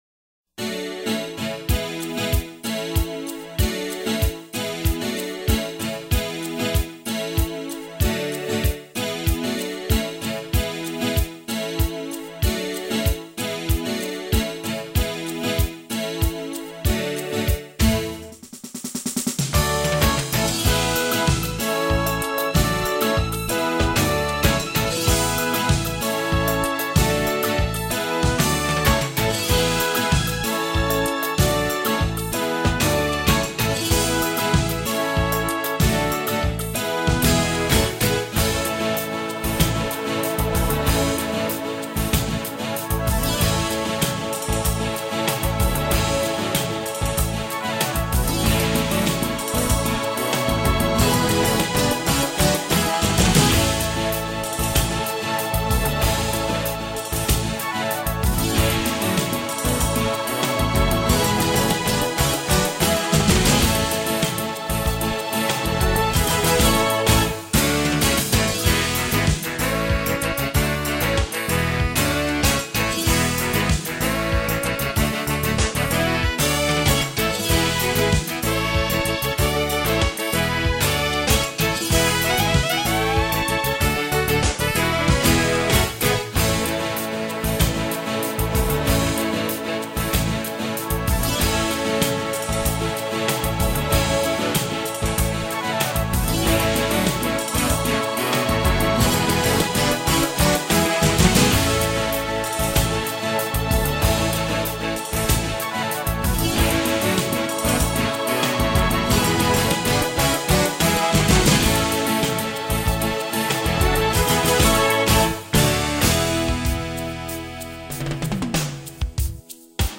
This is the happiest tune you'll find here.
Demonstrates my saxophone arrangements.